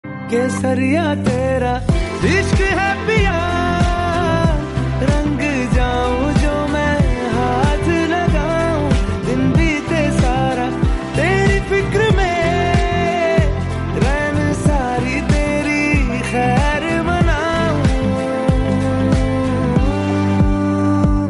Enchanting and soulful
a harmonious fusion of tradition and modernity